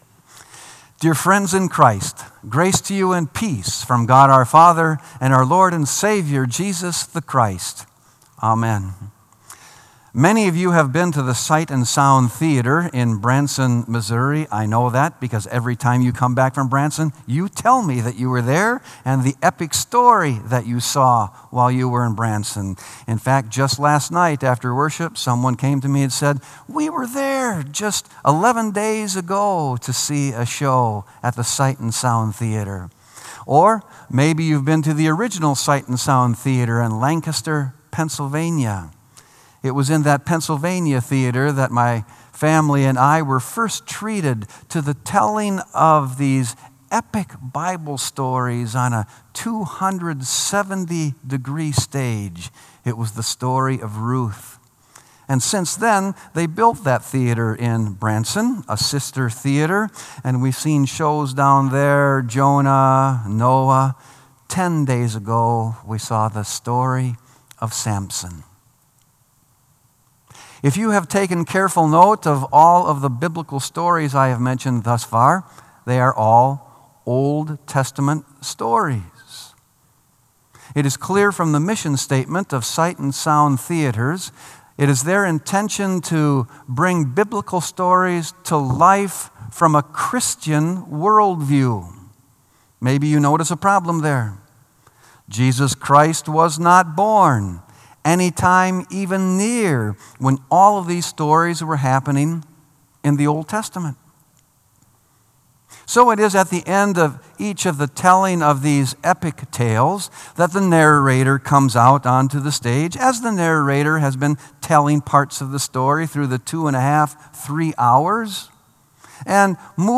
Sermon “By Grace, Through Faith”